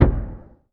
BUTTON_Deep_Smooth_Click_mono.wav